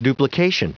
Prononciation du mot duplication en anglais (fichier audio)